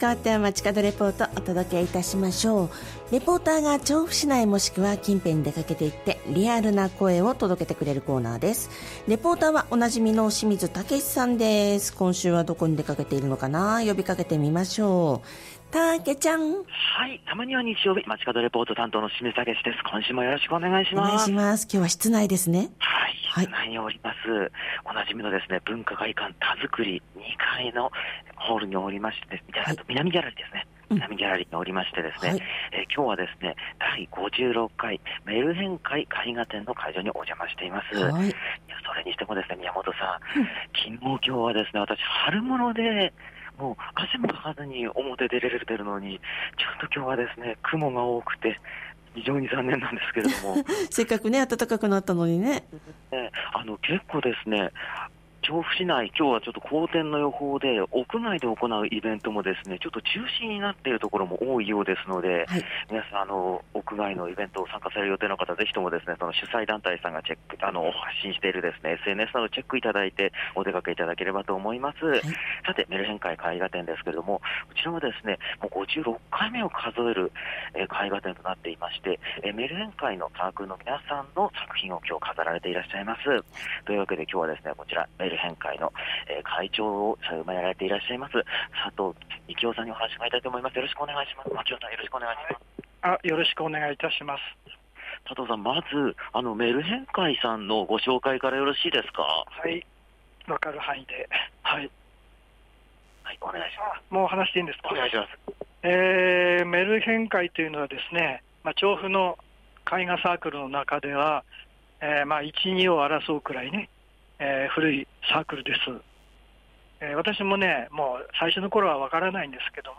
お届けした本日の街角レポートは、文化会館たづくりで行われている「第56回メルヘン会絵画展」のレポートです！！